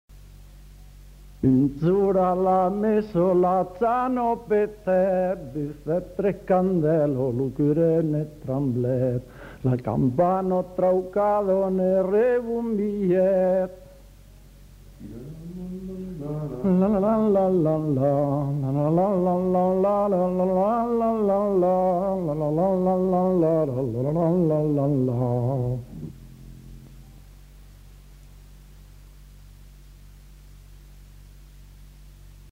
Aire culturelle : Haut-Agenais
Genre : chant
Effectif : 1
Type de voix : voix d'homme
Production du son : chanté
Danse : bourrée